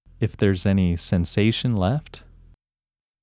IN – First Way – English Male 24